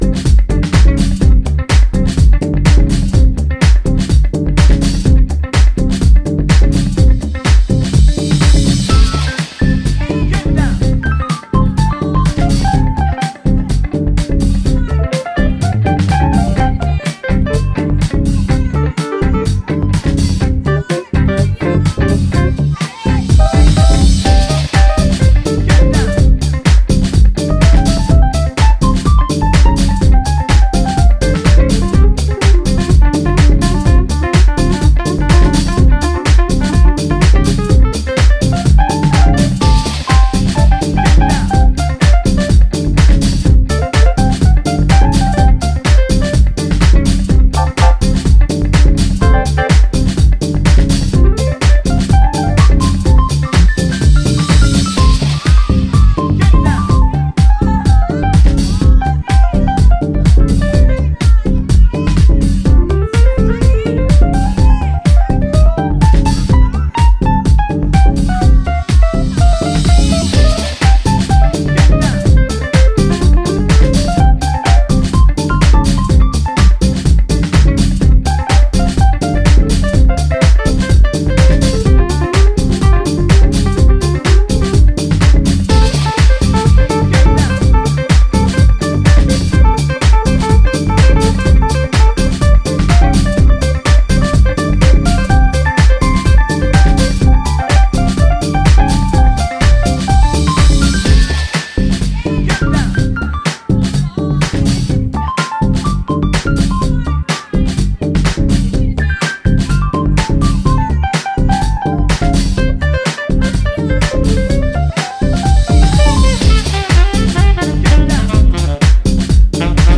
deep house
irresistibly funky